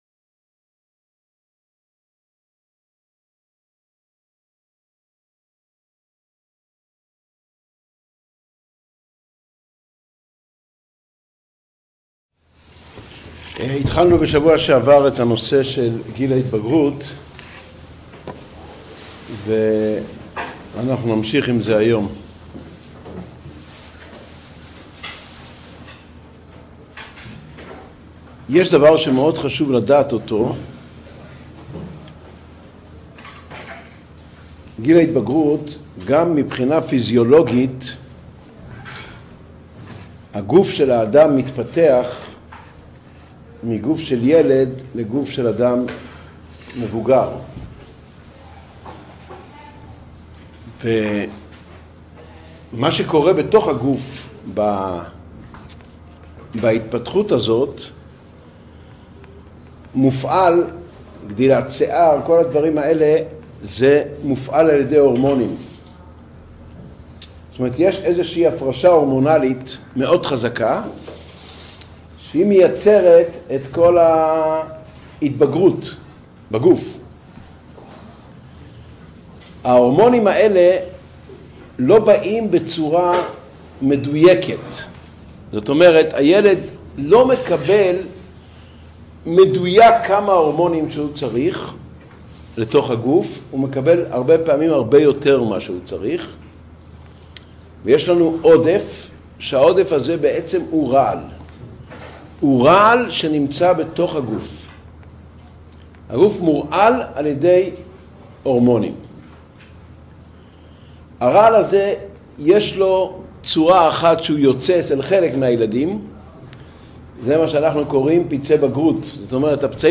Урок № 5.